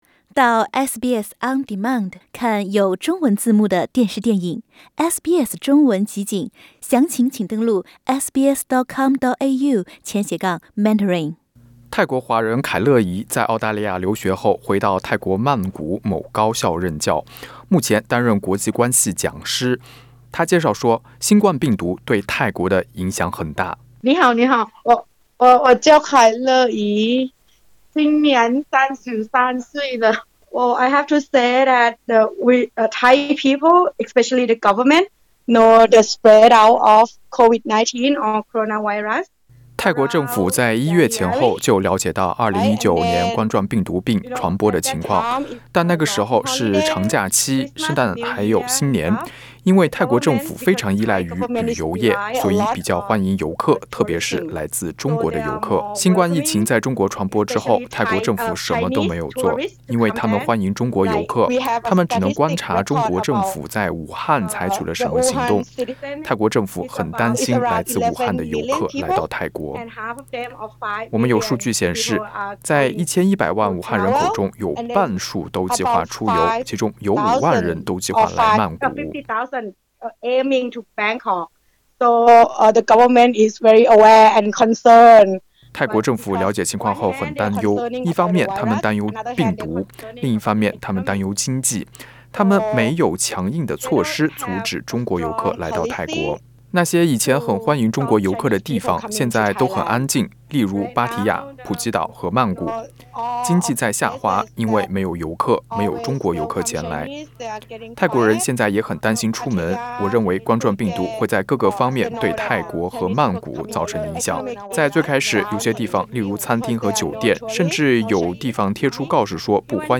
在采访中她还询问SBS普通话记者是否了解这些中国学生在泰国做了些什么，有没有自我隔离。“